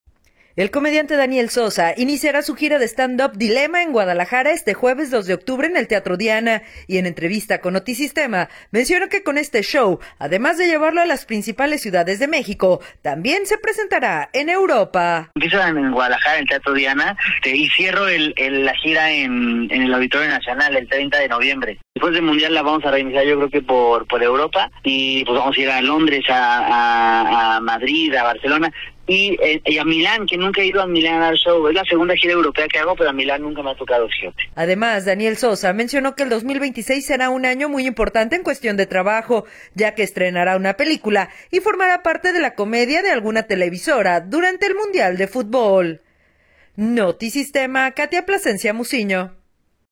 El comediante Daniel Sosa iniciará la gira de Stand Up titulado “Dilema” en Guadalajara este jueves 2 de octubre en el Teatro Diana, y en entrevista con Notisistema mencionó que con este show, además de llevarlo a las principales ciudades de México, […]